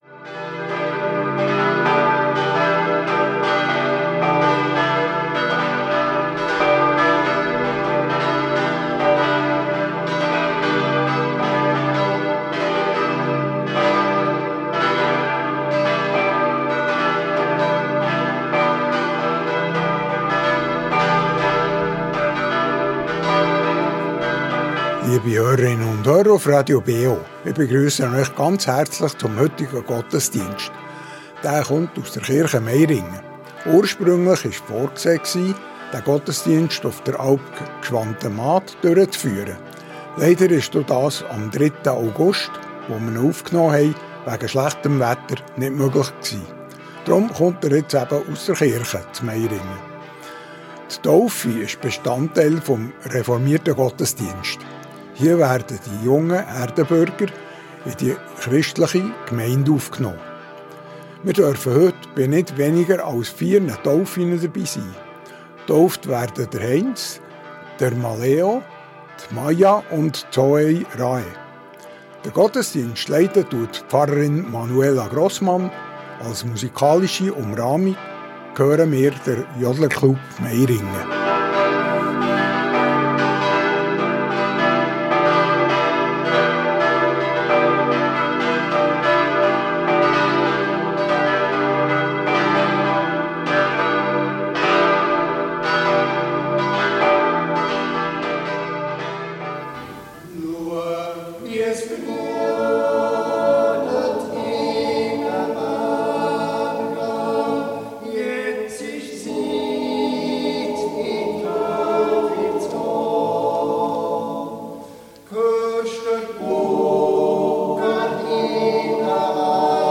Reformierte Kirche Meiringen ~ Gottesdienst auf Radio BeO Podcast